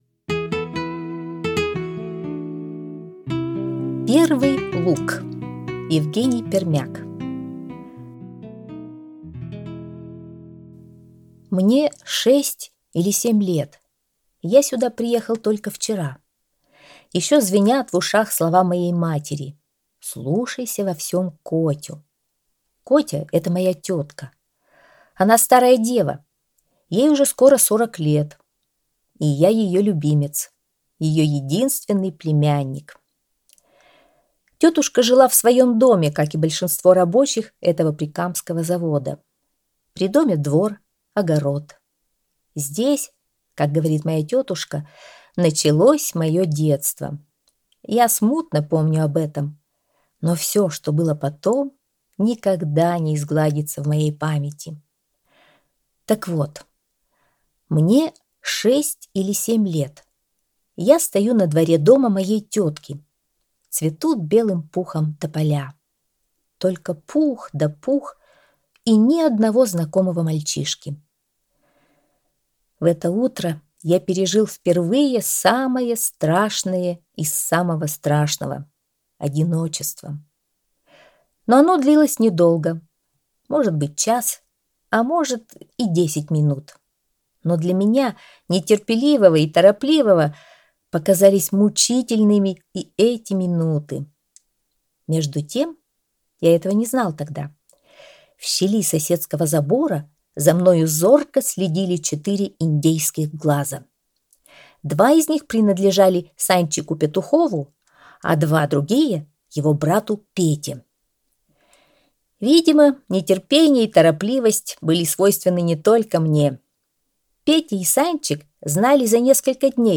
Аудиорассказ «Первый лук»